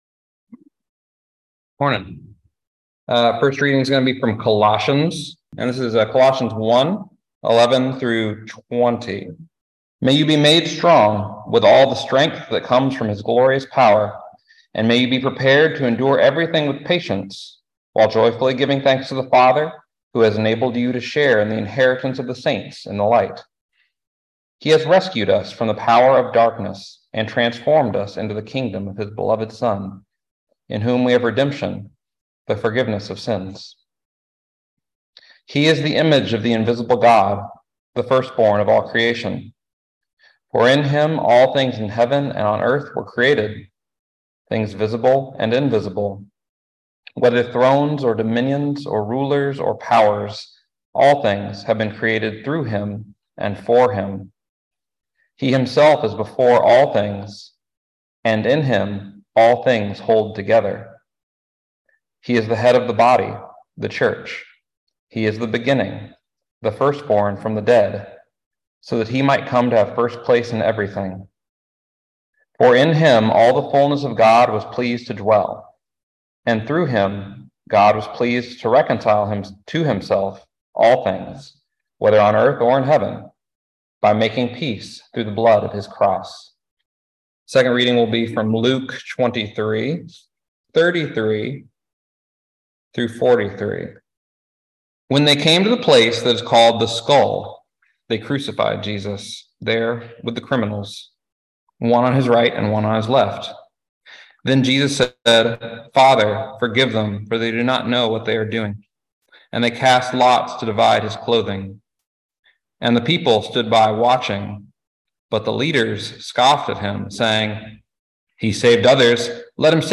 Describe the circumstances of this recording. Listen to the most recent message from Sunday worship at Berkeley Friends Church, “The Crucified King.”